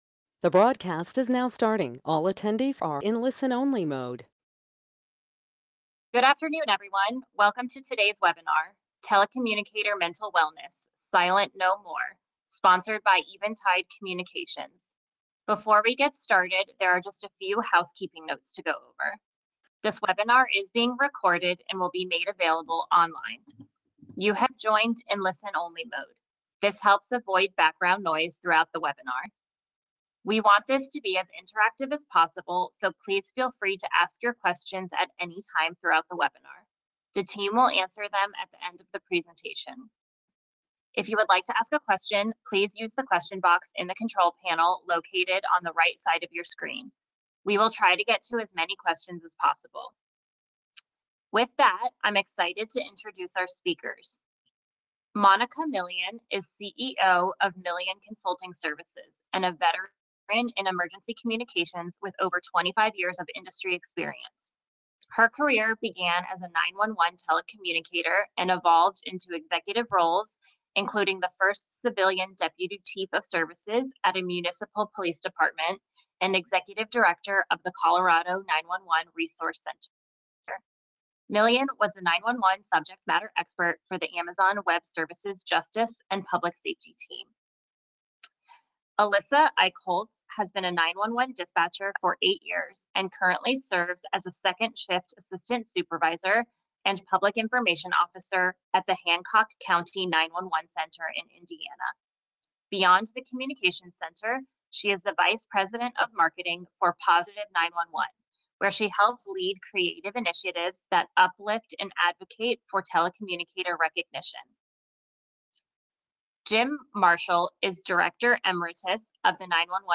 Webinar
Webinar-Telecommunicator-Mental-Wellness-Silent-No-More-Eventide-Communications.mp3